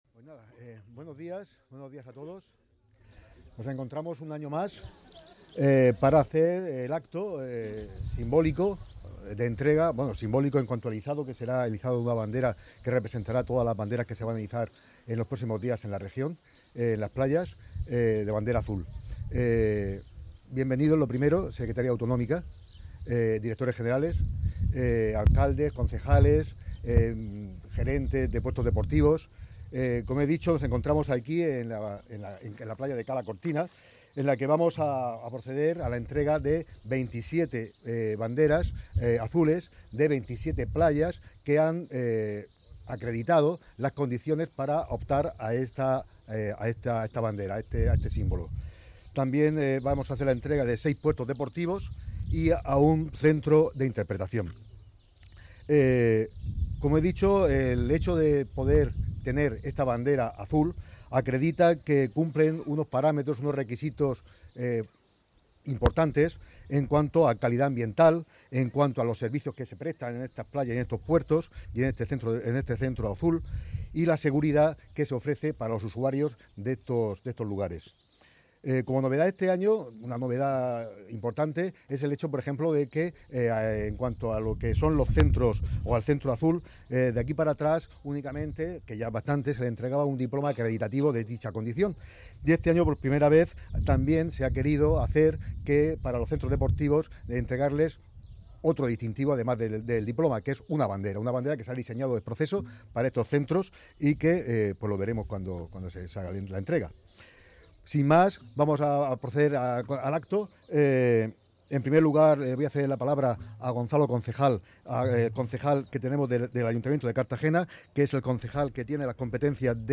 Declaraciones Gonzalo L�pez
La entrega de las Banderas Azules se ha llevado a cabo este viernes, 31 de mayo, en la playa cartagenera de Cala Cortina.